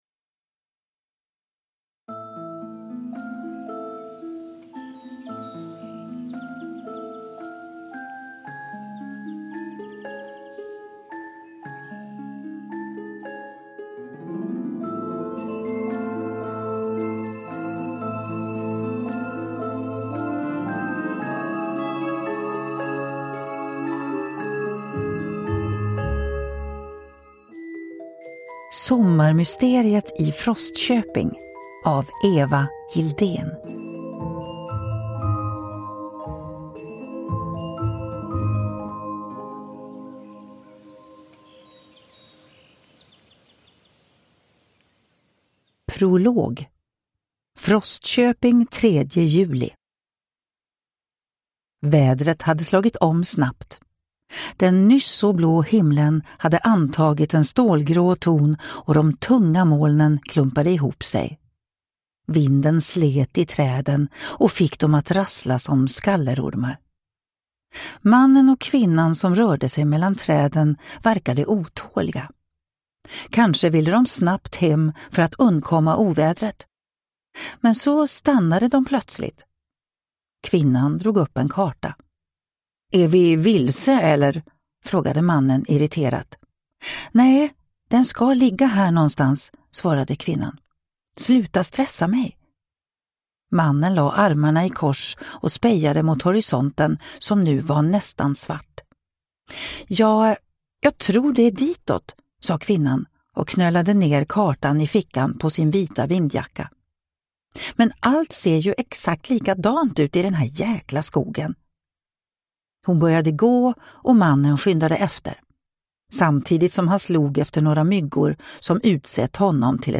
Sommarmysteriet i Frostköping (ljudbok) av Eva Hildén